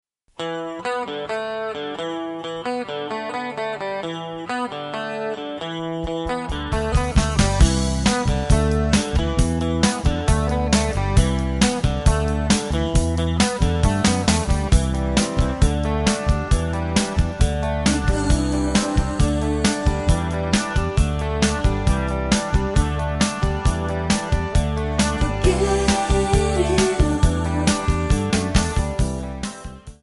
Backing track Karaoke
Pop, Rock, 1990s